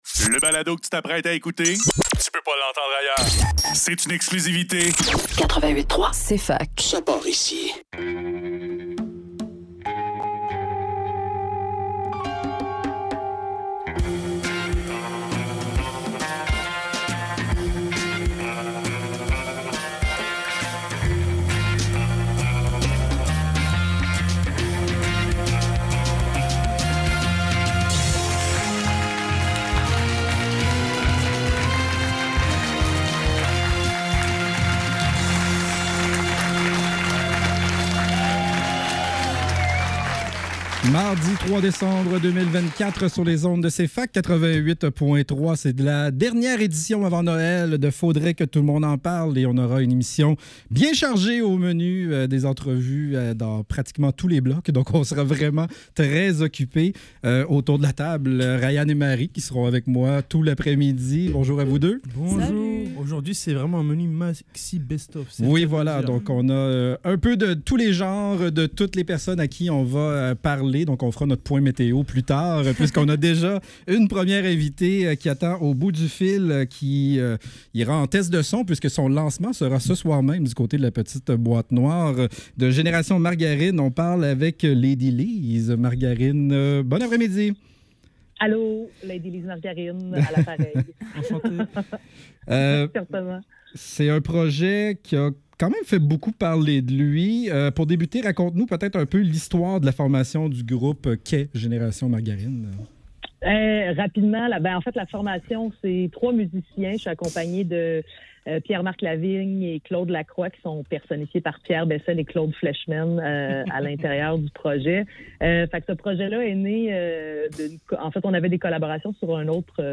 Faudrait que tout l'monde en parle - Entrevue avec Génération Margarine - 3 décembre 2024